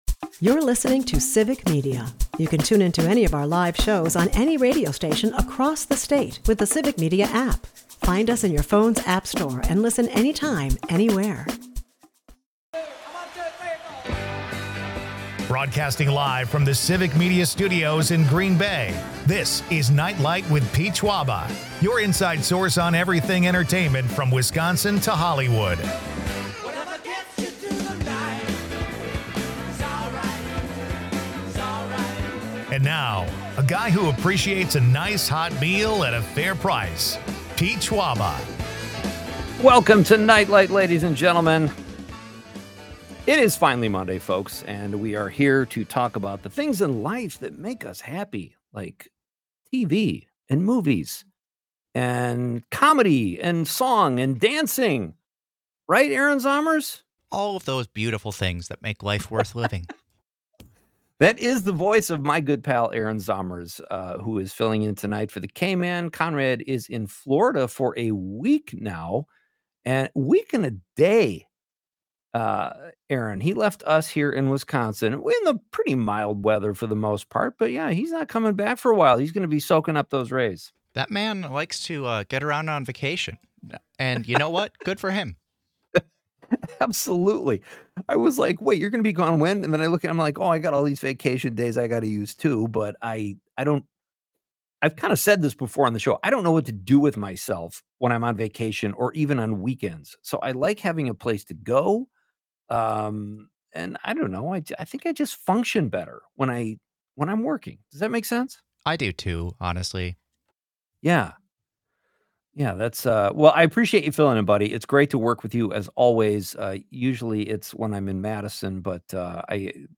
We take your calls and texts on why mashed potatoes are clearly the superior side.